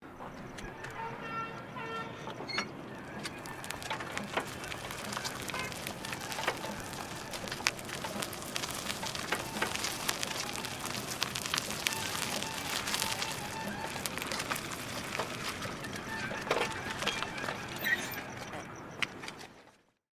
Rickshaw Wheels on Gravel
SFX
yt_1o-5RqAFTUc_rickshaw_wheels_on_gravel.mp3